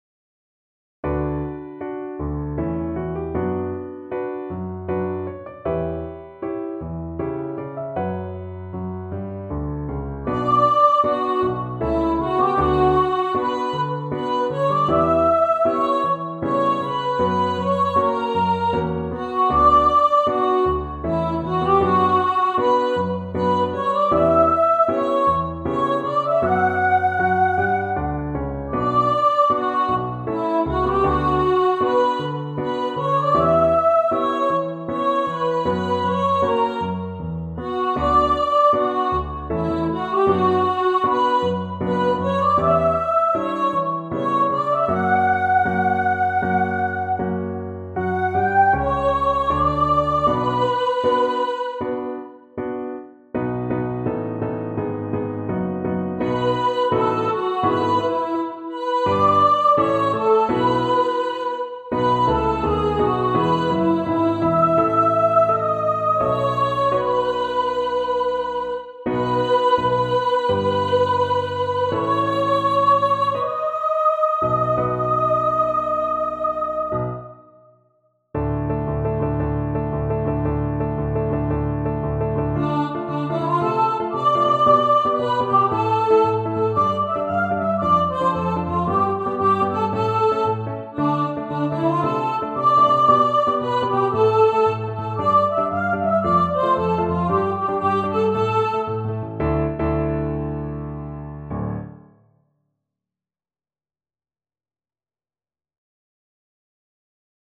Jordens-Soejler-Ensemble-Audition-suite-SOPAN-TENOR.mp3